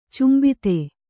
♪ cmbite